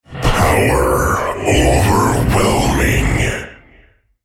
Archons sounded like godly entities overflowing with so much energy that they could barely get their words out. StarCraft II archons sound more like an actor doing a raspy voice.